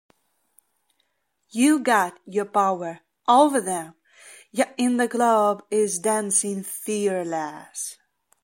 Dancing Fearless Voice Acapella Sample